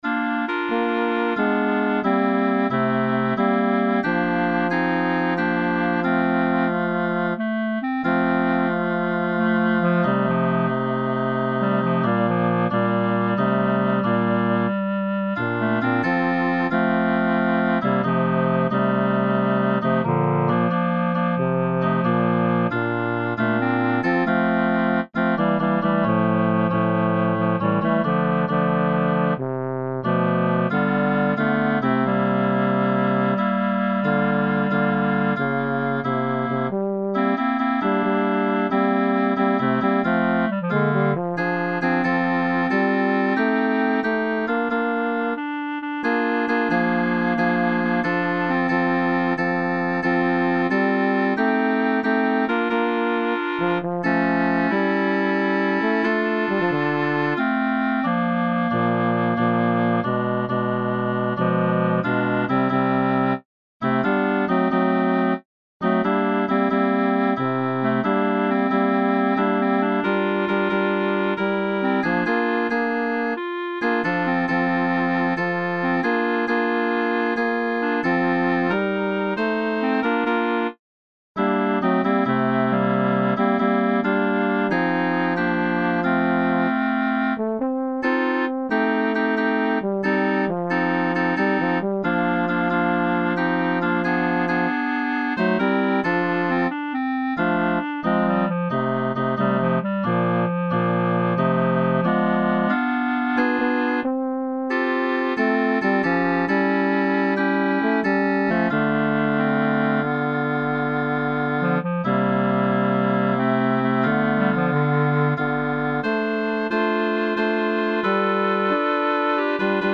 Midi Preview